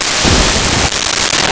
Explosion3
explosion3.wav